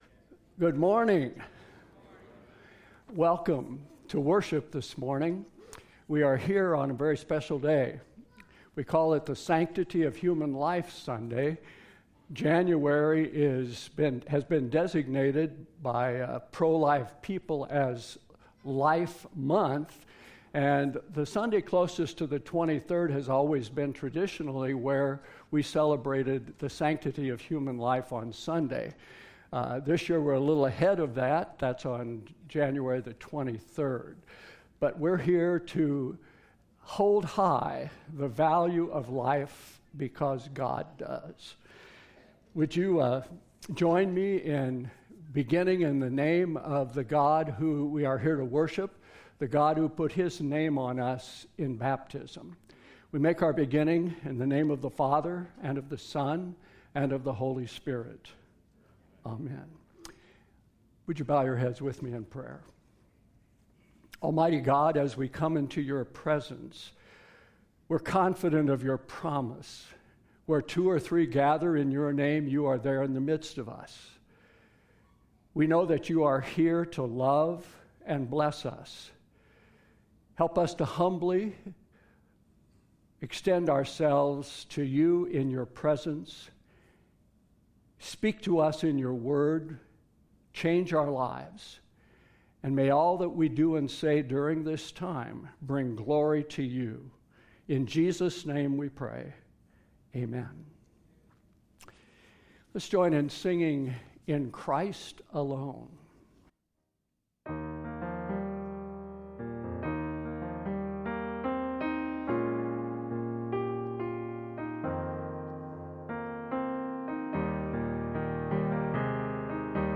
01-09-22 Service - St. Mark's Lutheran Church and School
2022-January-9-Complete-Service.mp3